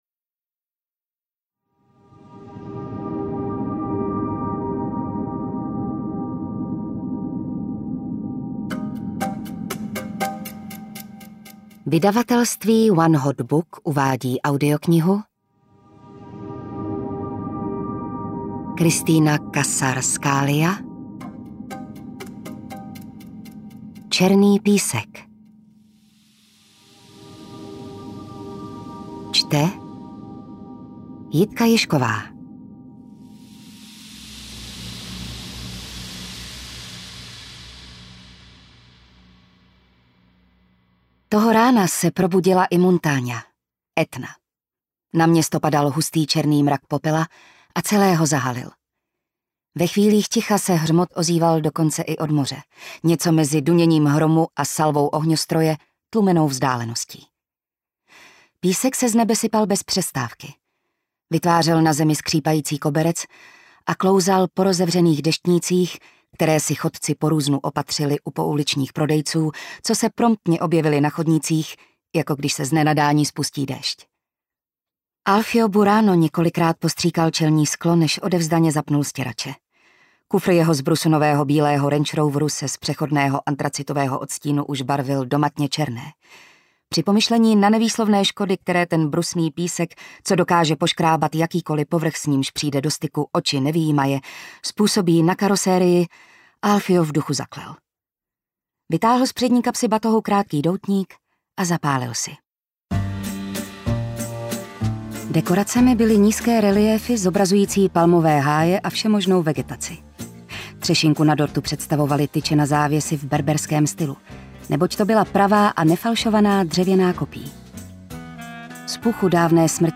Černý písek audiokniha
Ukázka z knihy